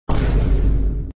Impact Sound Effect Free Download
Impact